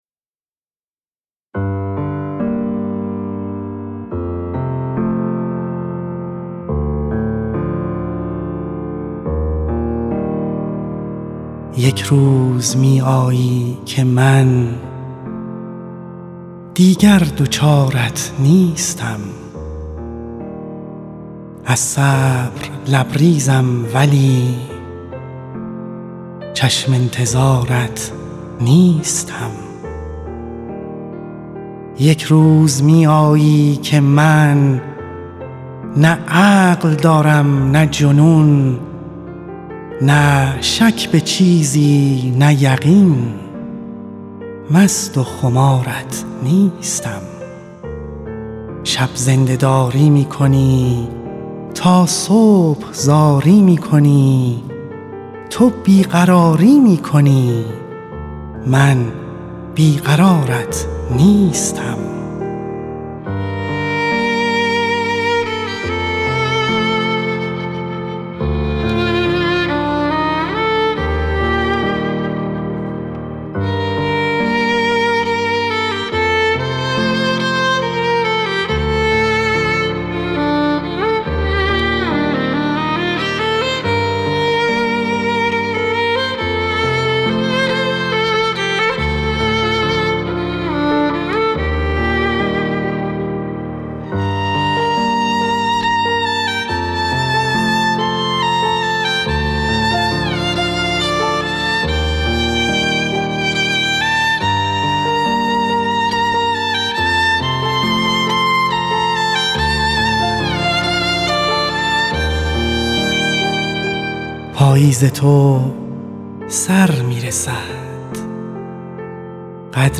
غزلی را از او با عنوان «یک روز می‌آیی که من دیگر دچارت نیستم» خوانده و در اختیار ایسنا گذاشته است.